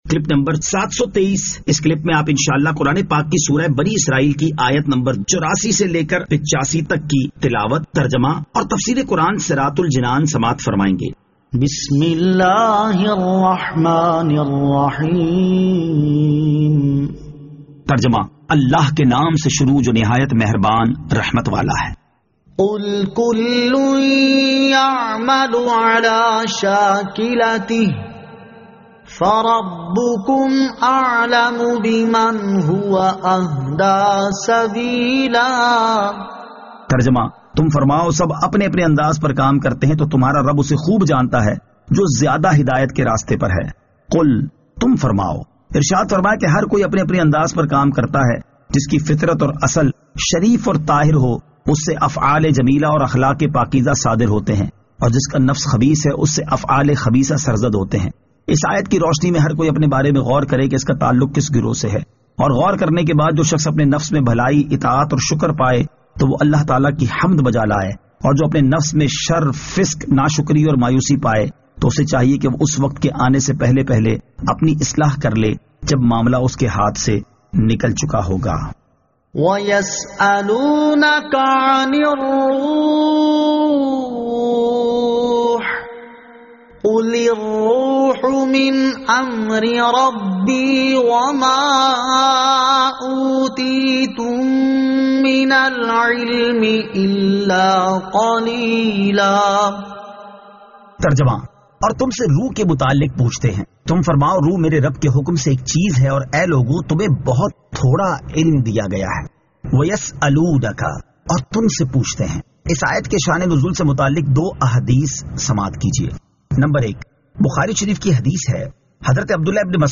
Surah Al-Isra Ayat 84 To 85 Tilawat , Tarjama , Tafseer